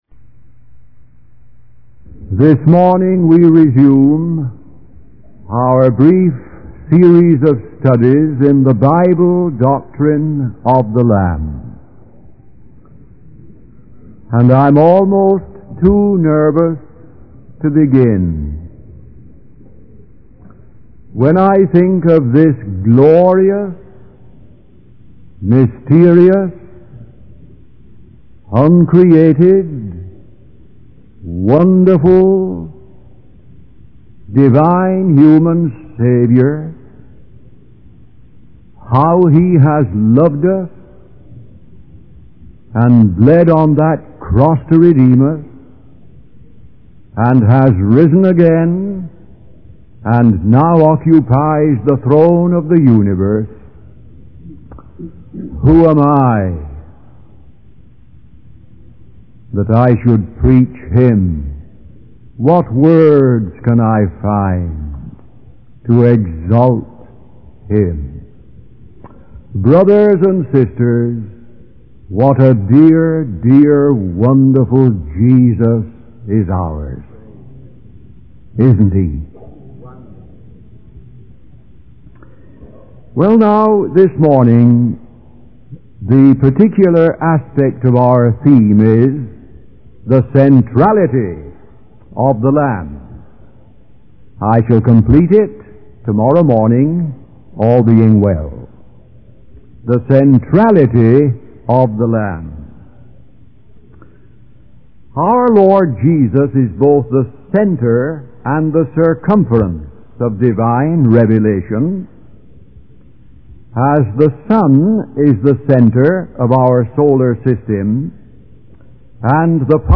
In this sermon, the preacher focuses on the significance of Isaiah 53 and the role of the Lamb in the redemption of humanity.